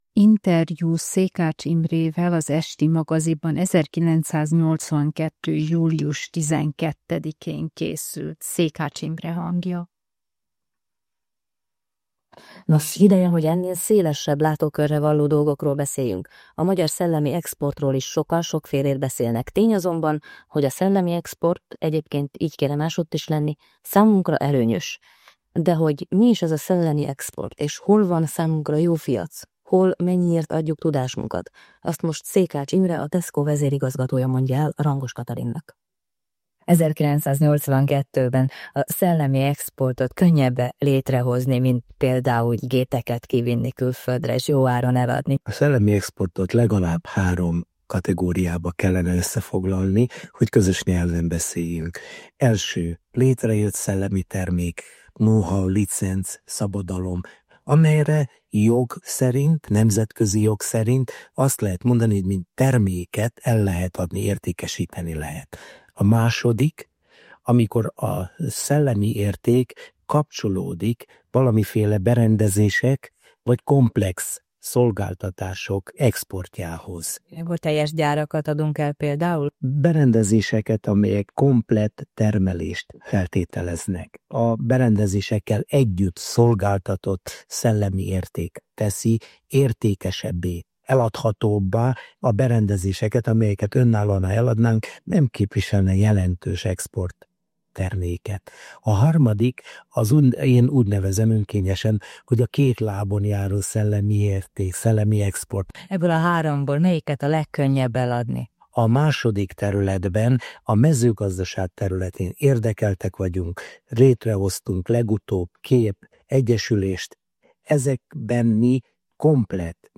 Hangarchívum